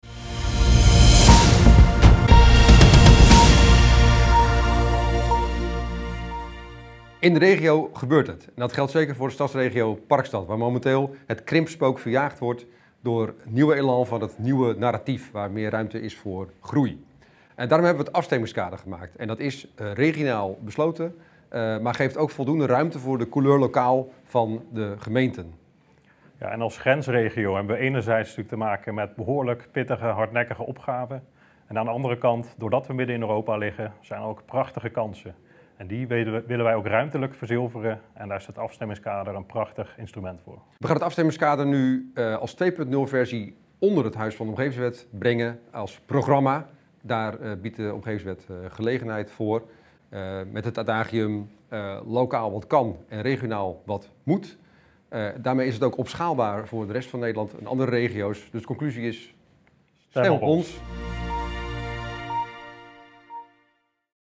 Pitch Parkstad